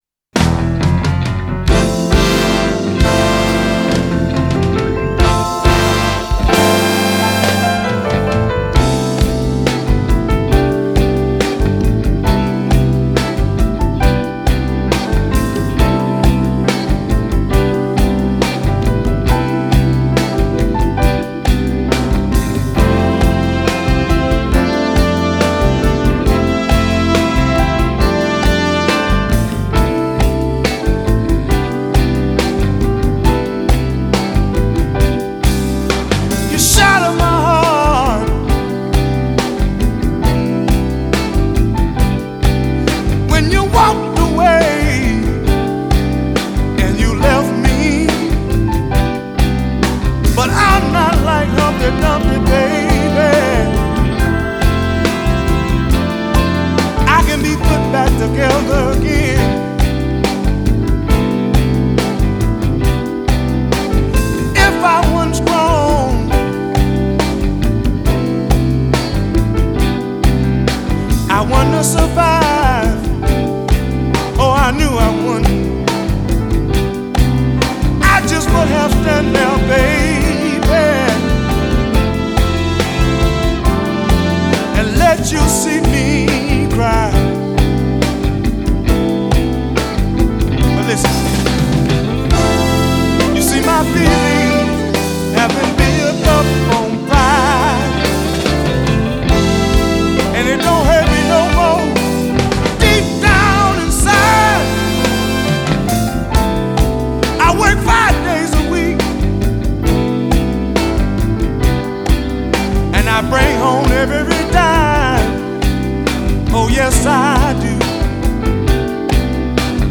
stunning stepper